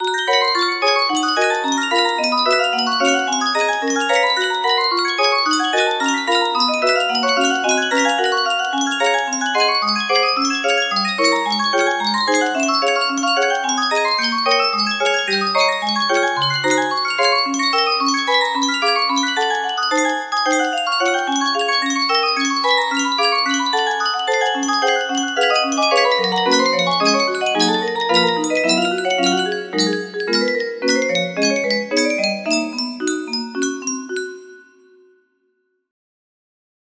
Chopin - Etude Op.25 No.9 Butterfly music box melody
Full range 60